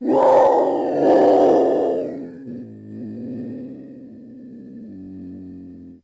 sounds_leopard_growl_01.ogg